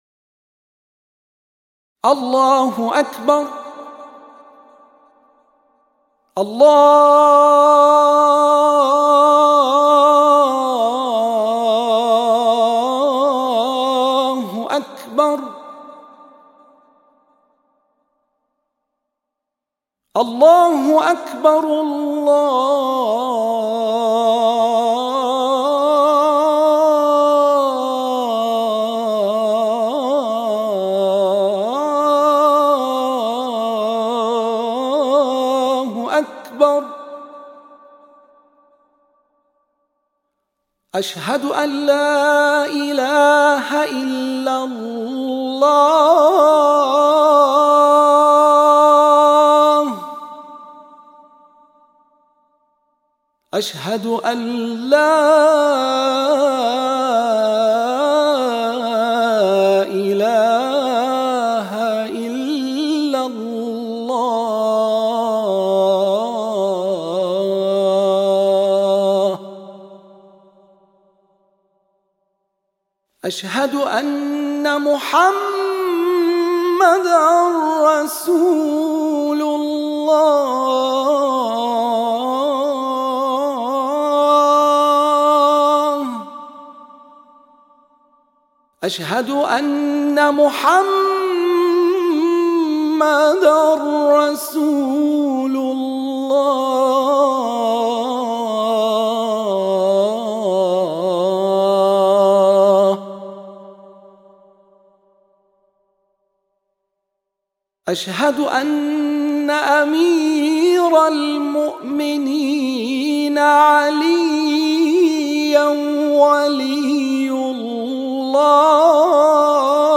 این اذان در اصل و اساس یعنی انتخاب مقام نهاوند، و از حیث شاکله نغمه‌ها و ریزنغمه‌ها و تحریرها و همه‌ آرایه‌های صوتی و لحنی، یک سبک ابتکاری و ابداع است و مطلقاً وامدار هیچ اذانی نیست و برآمده از دل و جان یک قاری و مبتهل شیعه‌ ایرانی است.
و این‌گونه 6 فراز از اذان را در تنهایی گفتم که با حسّ و حال کامل، آنچنان که آرزو داشتم، و تازه این عزیز ماندند و کار ادیت و آماده‌سازی اذان را به انجام رساندند و این جز از سر عشق و ایمان نیست.